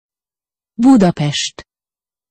Budapest(to correct word is Buda phonetic: